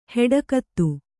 ♪ heḍa kattu